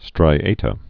(strī-ātə)